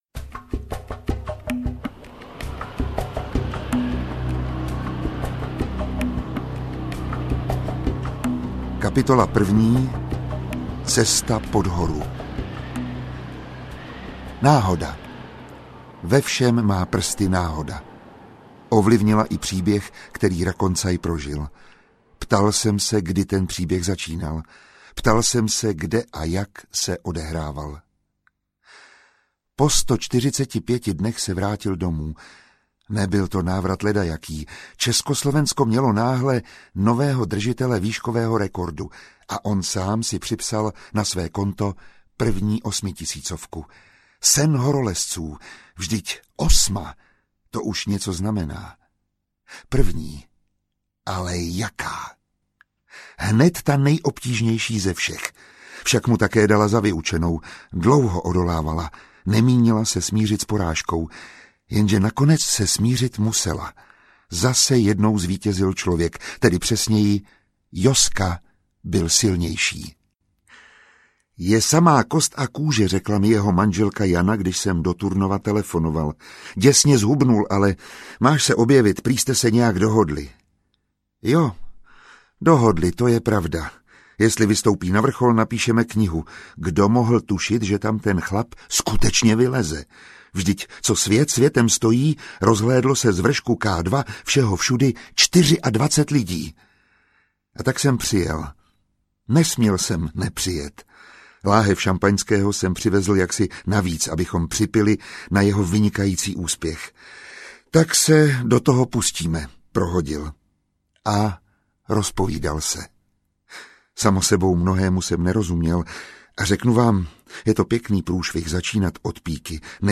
K2 - 8611 m audiokniha
Strhující příběh Josefa Rakoncaje, dlouhá léta jediného muže na světě, který zdolal K2 dvakrát, čte Miroslav Táborský.
Ukázka z knihy
• InterpretMiroslav Táborský